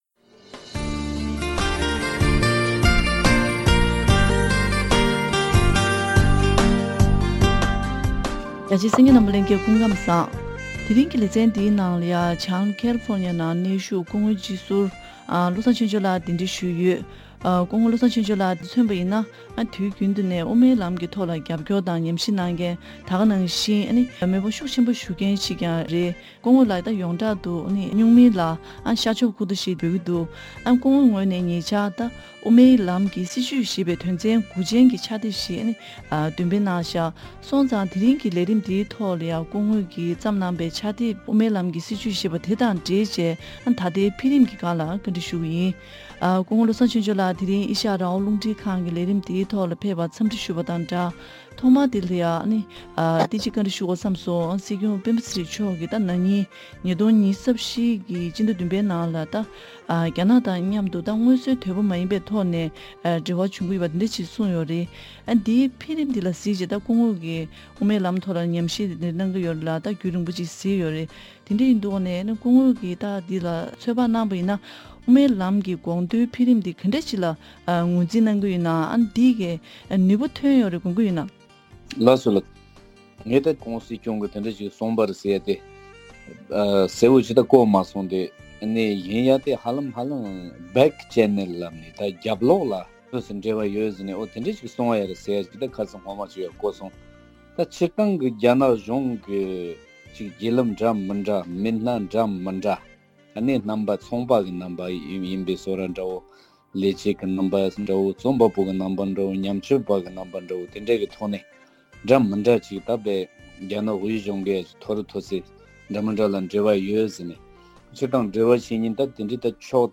ཉེ་ལམ་དབུ་མའི་ལམ་གྱི་སྲིད་བྱུས་དང་འབྲེལ་བའི་ཕྱག་དེབ་རྩོམ་བྲིས་གནང་སྟེ་འདོན་སྤེལ་གནང་མཁན་བོད་མི་མང་སྤྱི་ཟུར་བློ་བཟང་ཆོས་འབྱོར་ལགས་དང་ལྷན་༧གོང་ས་༧སྐྱབས་མགོན་ཆེན་པོ་མཆོག་གི་དགོངས་གཞི་དང་བོད་མིའི་སྒྲིག་འཛུགས་ཀྱིས་རྒྱ་ནག་མཉམ་ ༢༠༢༤ ཟླ་ ༧ པའི་ནང་རྒྱབ་སྒོ་ནས་འབྲེལ་བ་བྱུང་ཡོད་པ་སོགས་ཀྱི་འཕེལ་རིམ་དང་འབྲེལ་ནས་བཀའ་དྲི་ཞུས་པའི་ལས་རིམ།